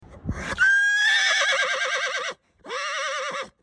Sound Effect - Horse Whinny 03 Bouton sonore